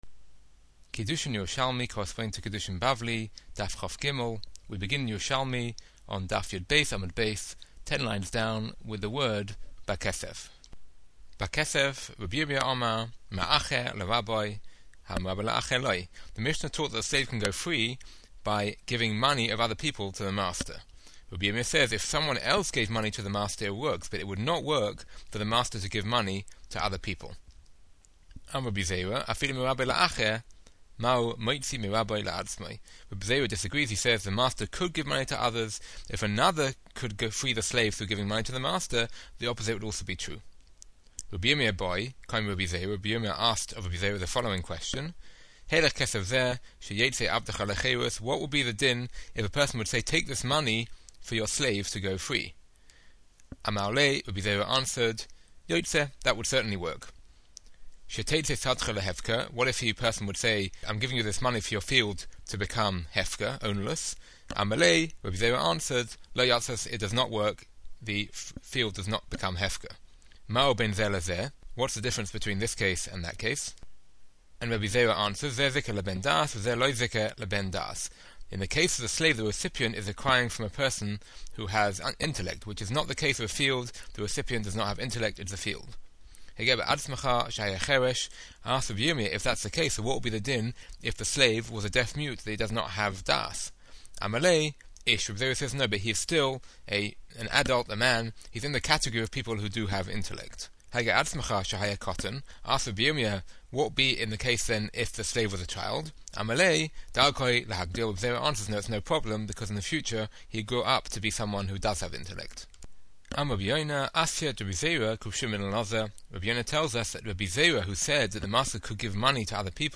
MP3's with detailed explanations of every Daf's Yerushalmi Matchup